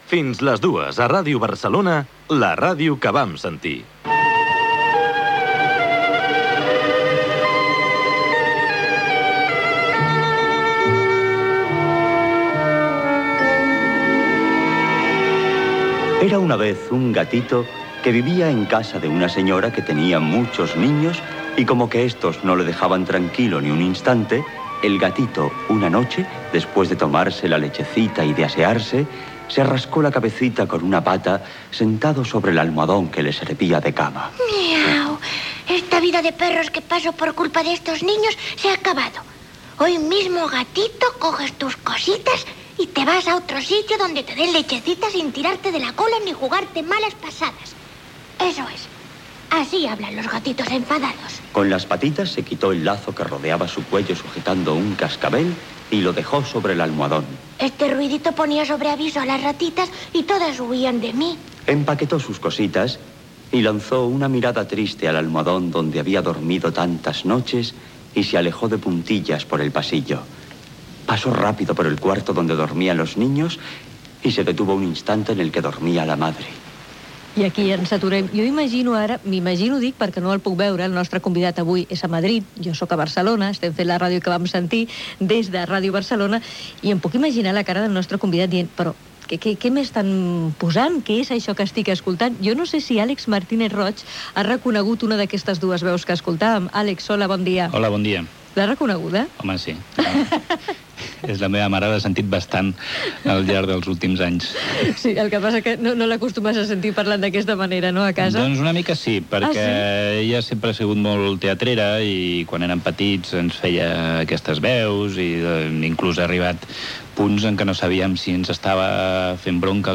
Indicatiu del programa.
Divulgació